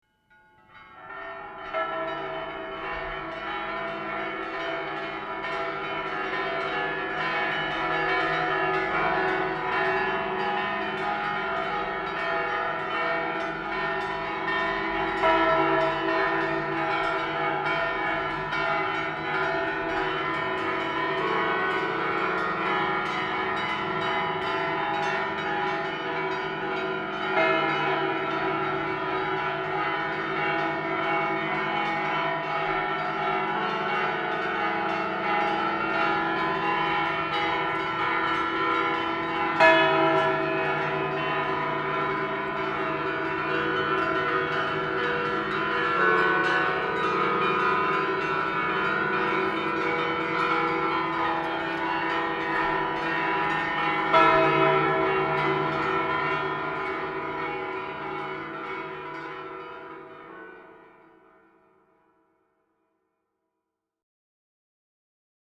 31 CAMPANES REPIC GENERAL Grup de Campaners de la Catedral
Santa Tecla Tarragona